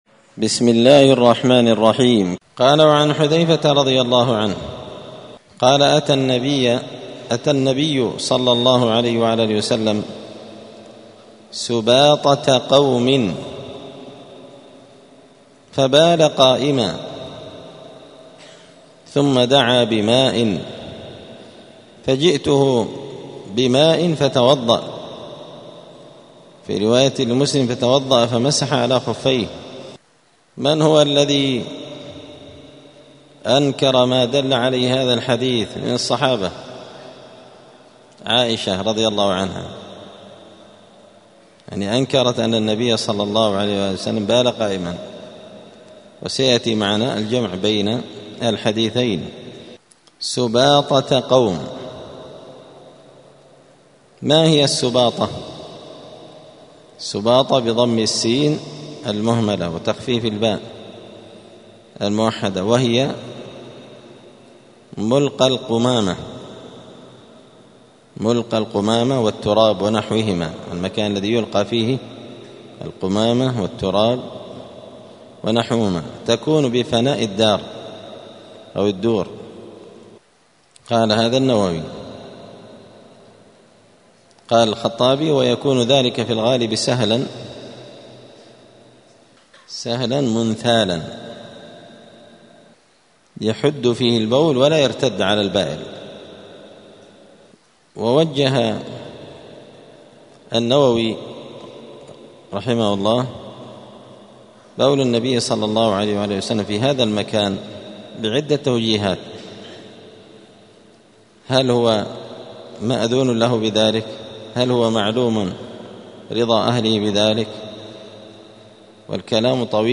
دار الحديث السلفية بمسجد الفرقان قشن المهرة اليمن
*الدرس السبعون [70] {باب الاستطابة حكم البول قائما}*